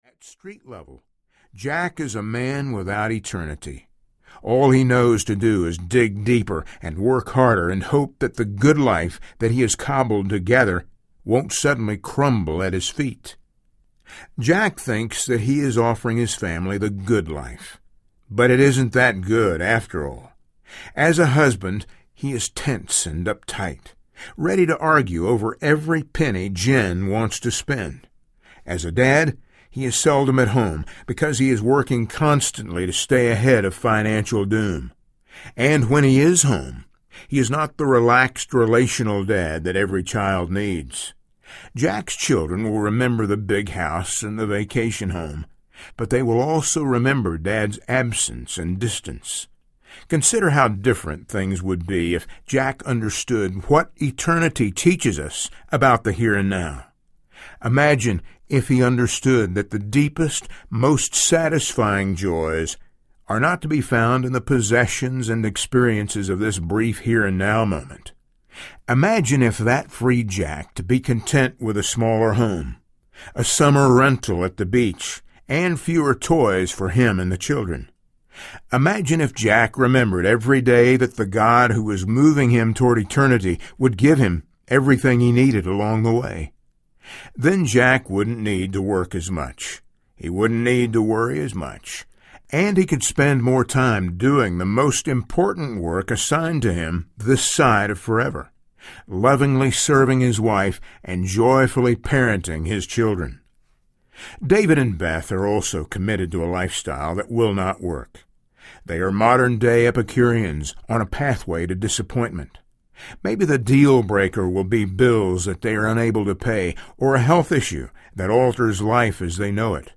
Forever Audiobook
Narrator